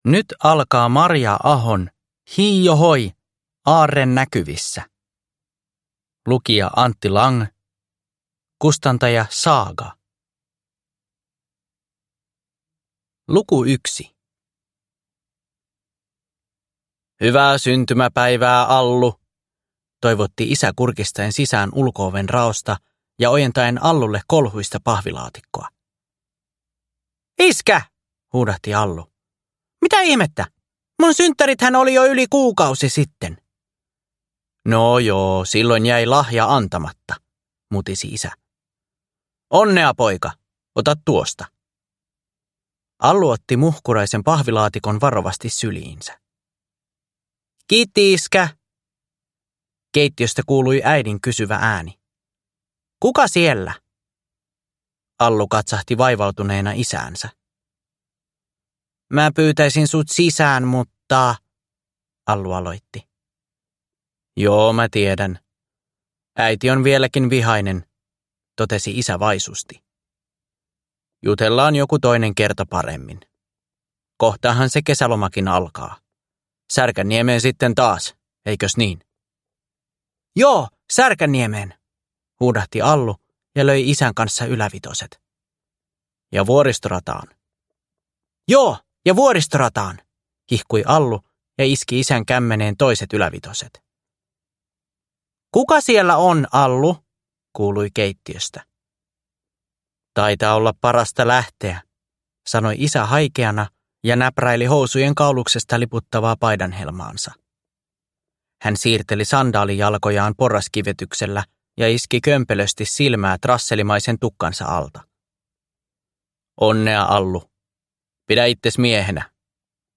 Hii-o-hoi! Aarre näkyvissä! (ljudbok) av Marja Aho